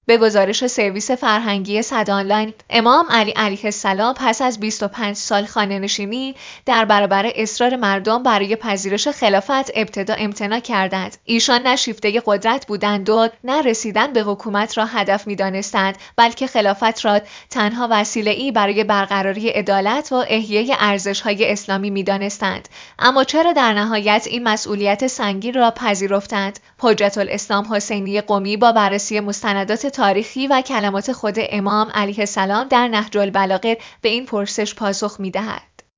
در سخنانی با محوریت شرح نهج‌البلاغه